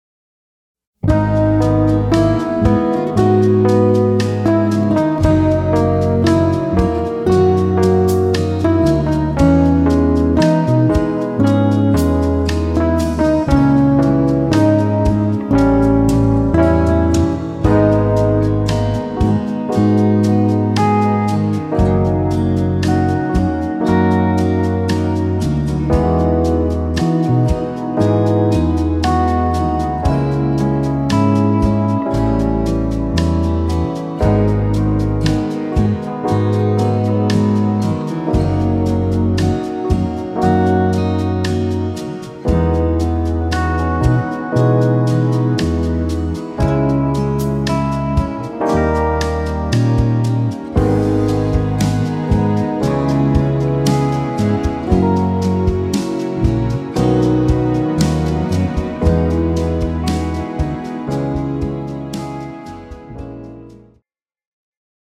pop ballad style
tempo 58 bpm
male backing track